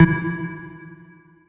key-movement.wav